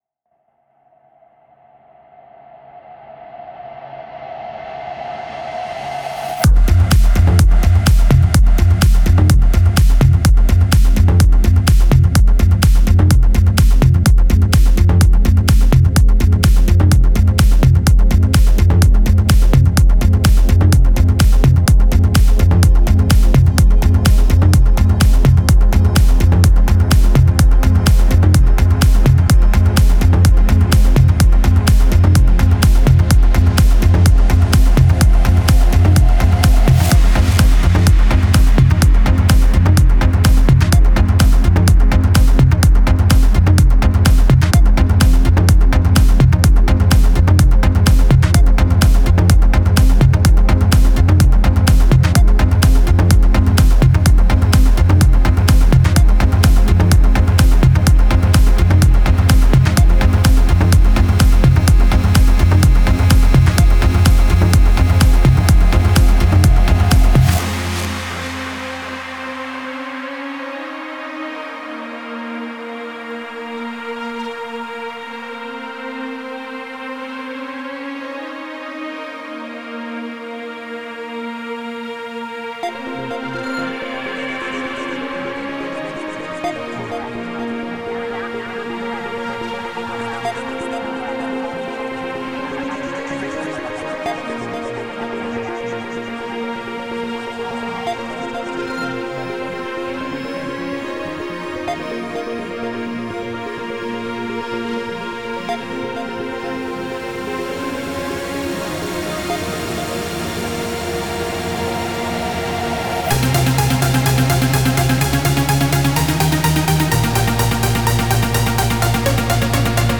Стиль: Trance / Progressive Trance